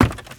High Quality Footsteps
STEPS Wood, Creaky, Run 18.wav